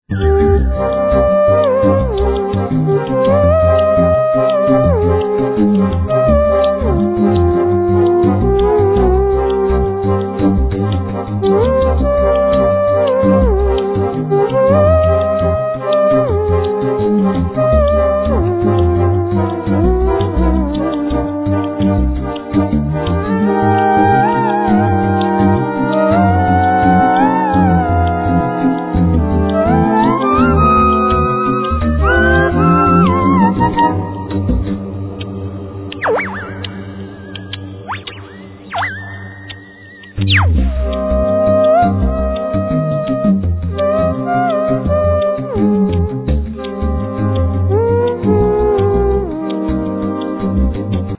Theremin, Midi-theremein, Sampling, Bass
Flute, Alt & Bass Flute, Clarinet, Bass clalinet
Violin samples
Double bass
Vocals
Guitar
Drums, Keyboards